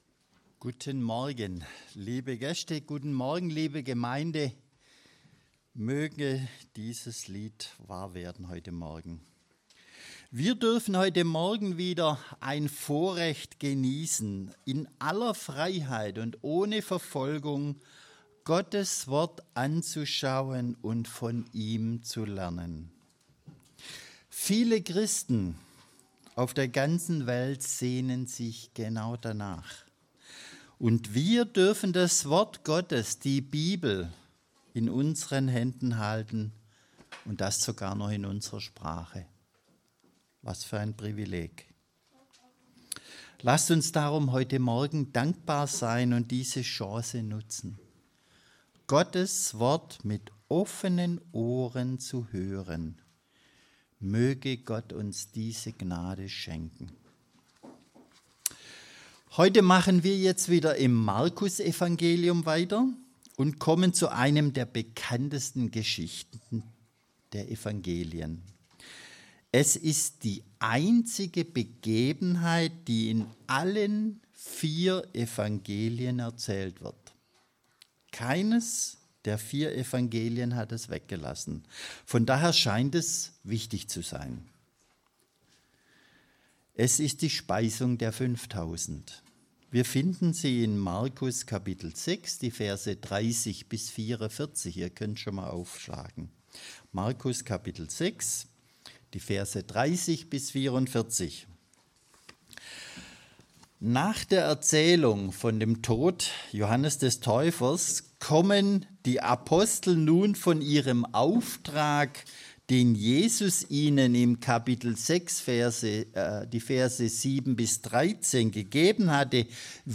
Predigtreihe: Markusevangelium